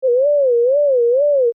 Create the bouba and kiki non-word sounds, by mimicking the duration and frequency (i.e. melody) of the spoken words ‘bouba’ and ‘kiki.’ Use a sound wave generator and pitch bend.
Audio 2: Bouba sound